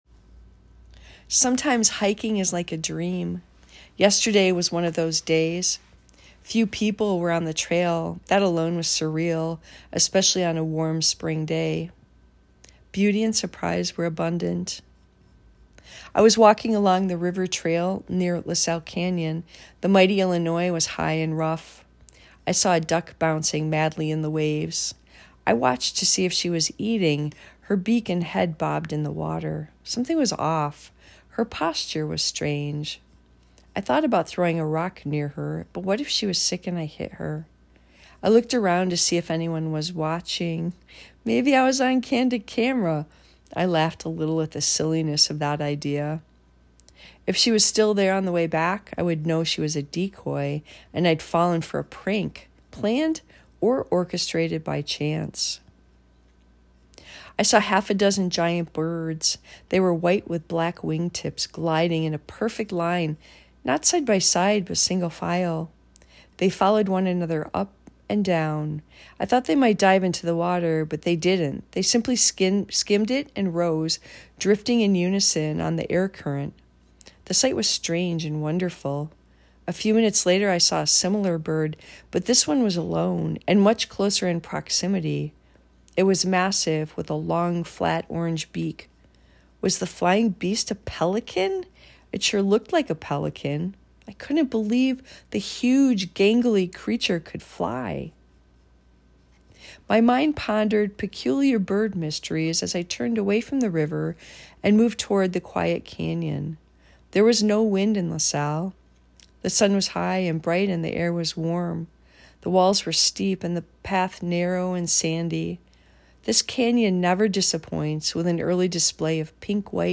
I found your voice very calming.